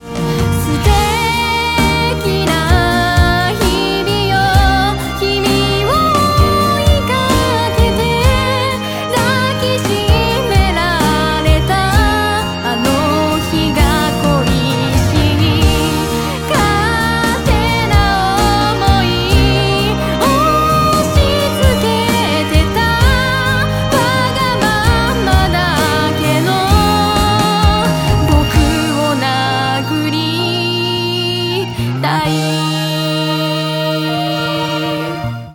歌声がめちゃくちゃクリアに聴こえるらしいですよ…！！
セルフMIXしている歌い手としては全然『簡易』レベルでは無く高クオリティだと感じました！
素敵な歌声です♪